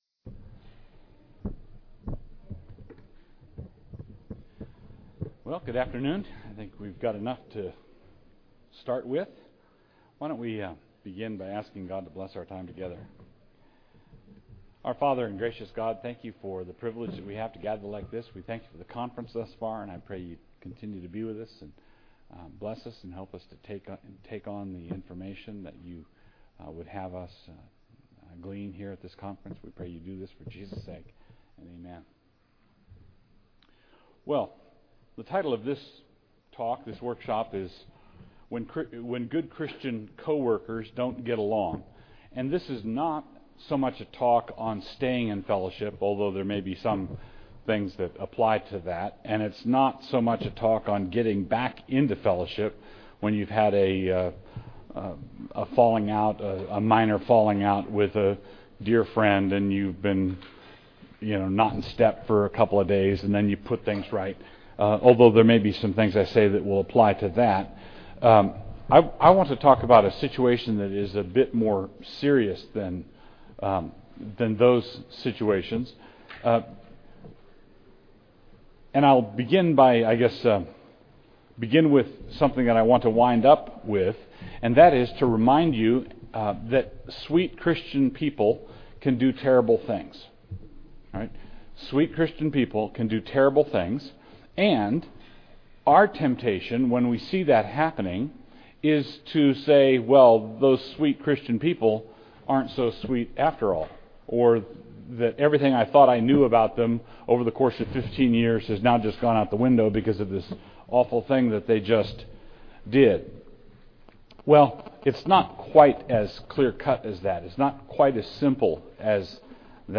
2009 Workshop Talk | 1:05:24 | All Grade Levels, Culture & Faith, Teacher & Classroom